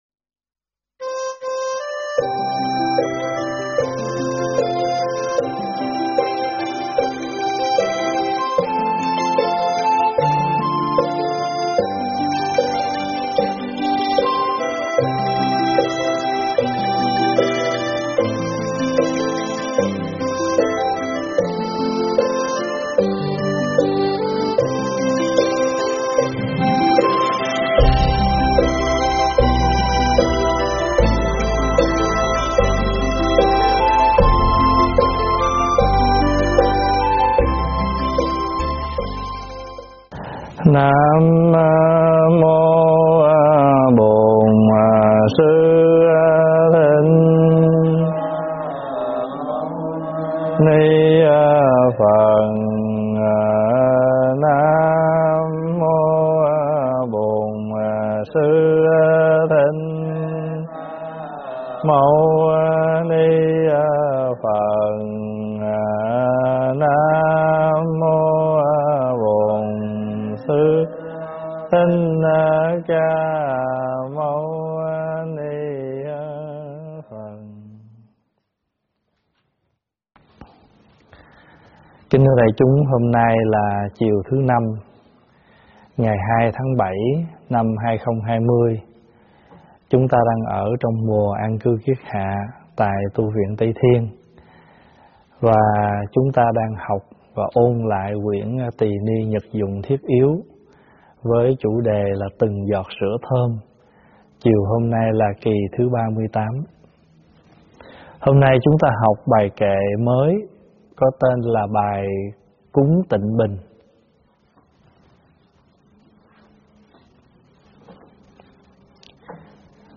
Nghe mp3 thuyết pháp Từng Giọt Sữa Thơm 38 - Kệ cúng tịnh bình
giảng tại Tv Trúc Lâm